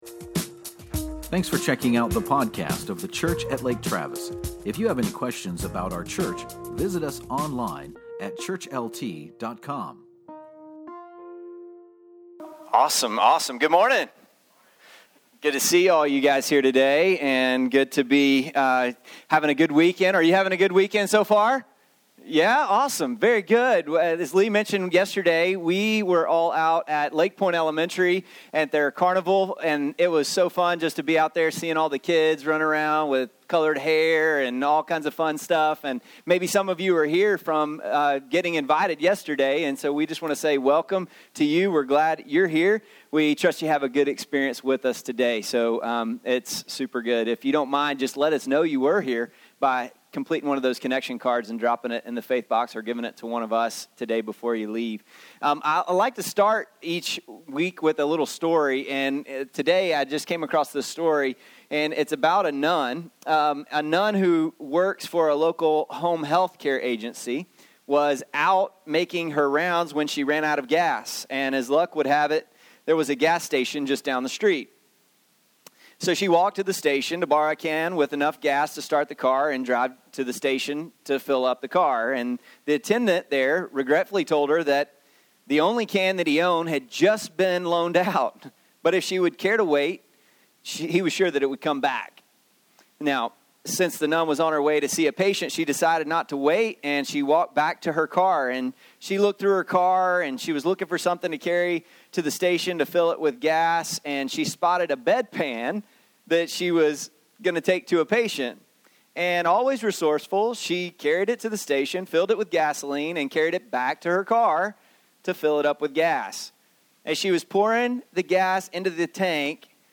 The object of our faith is vastly important. In this message, we walk through Hebrews 11:1-13 to begin the series on Faith: For Everyday Life.